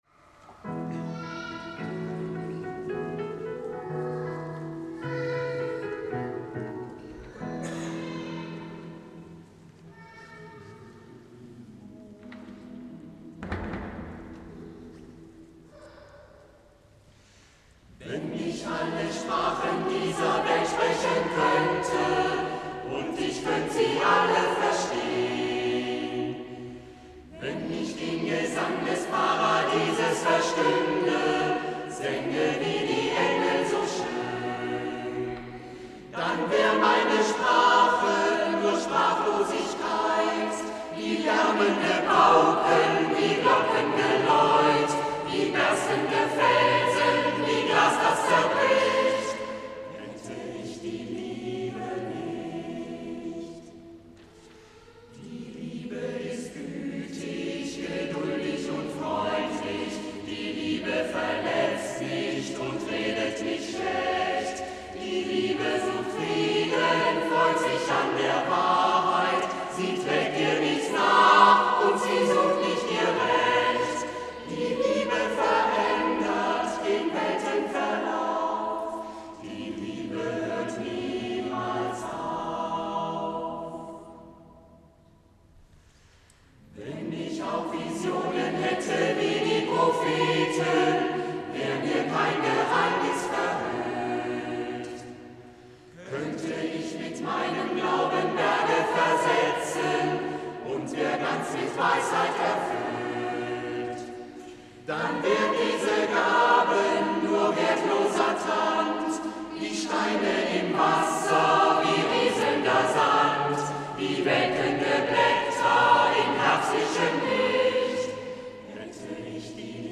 Musikalischer Abschluss der dritten Oktav 2013
Kinder- und Jugendchor
Projektchor